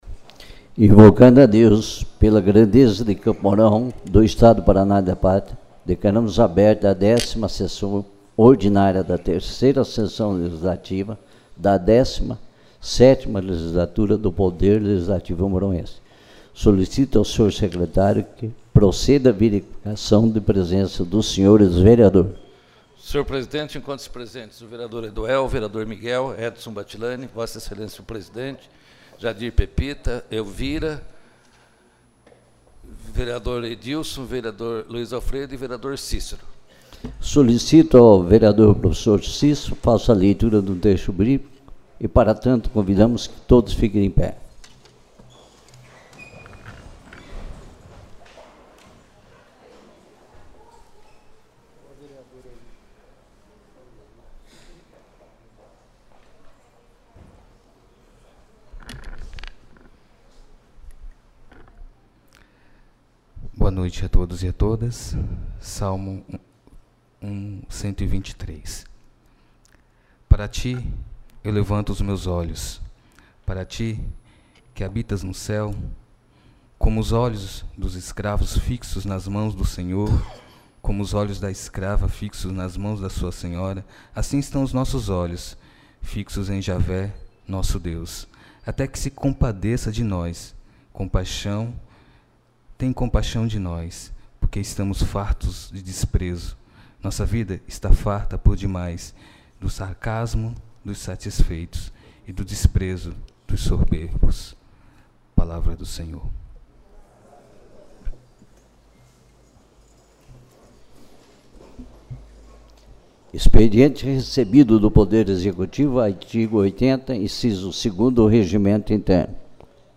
10ª Sessão Ordinária